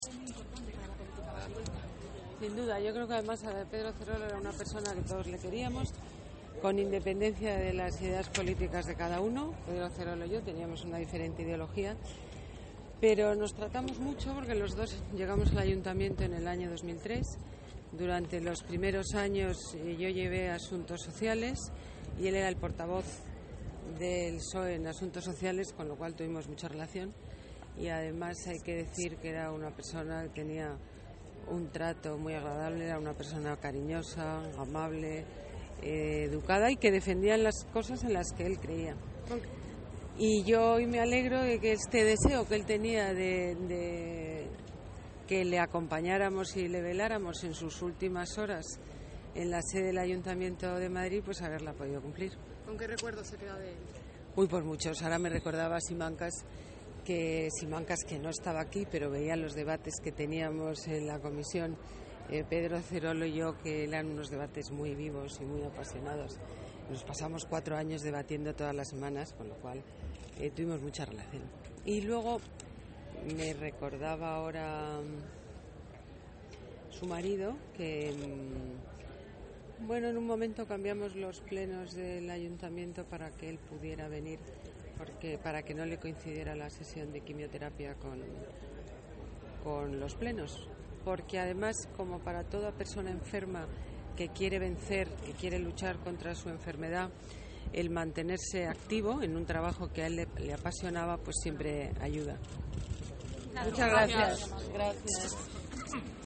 La alcaldesa se despide de Pedro Zerolo en la capilla ardiente instalada en la el Patio de Cristales de la Casa de la Villa
Nueva ventana:Declaraciones de la alcaldesa en la capilla ardiente de Pedro Zerolo